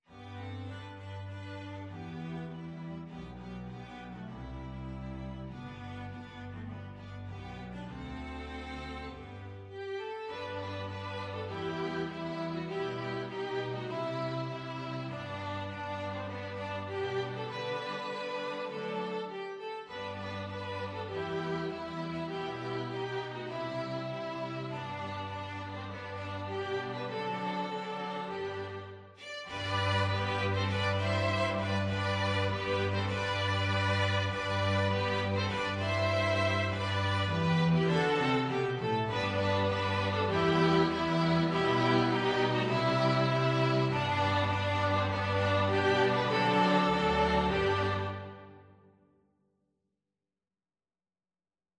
Violin 1Violin 2ViolaCelloDouble Bass
4/4 (View more 4/4 Music)
Moderato
String Ensemble  (View more Easy String Ensemble Music)
Traditional (View more Traditional String Ensemble Music)